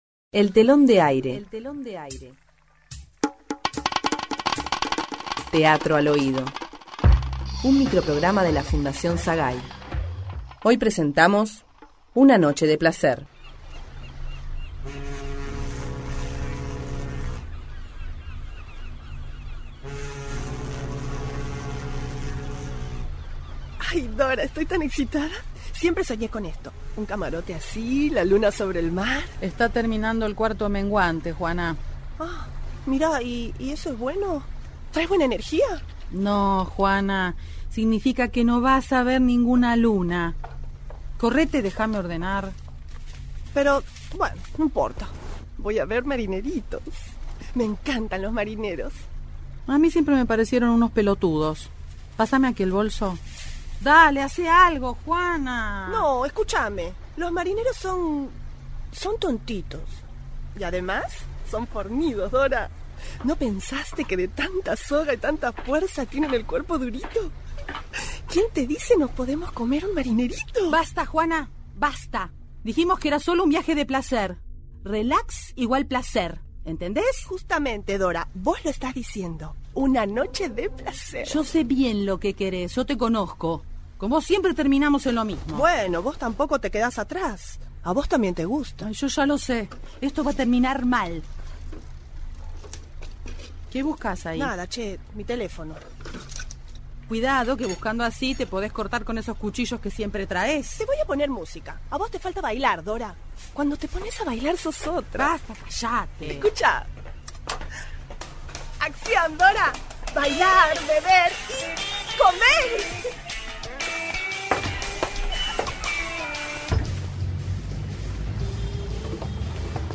Título: Una noche de placer. Género: Ficción. Sinopsis: Dos amigas, de viaje en un crucero, sólo quieren pasarla bien.
grabación en estudio